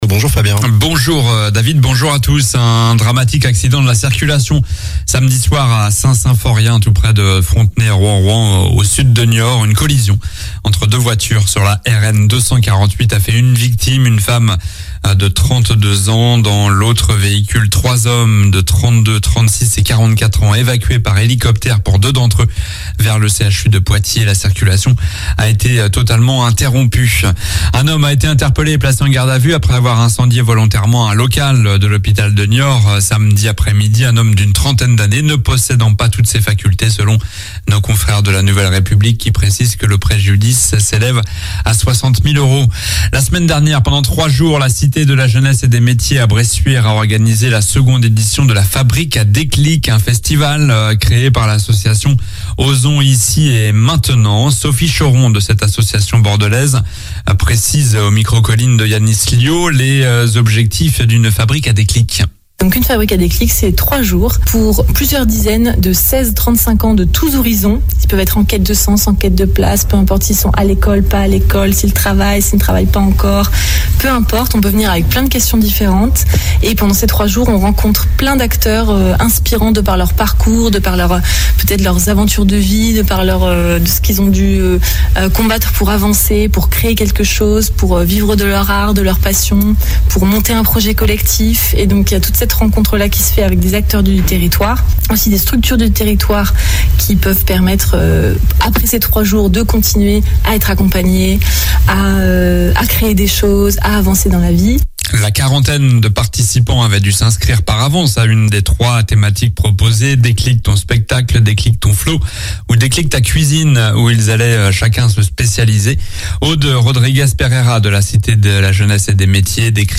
Journal du lundi 17 juillet (matin)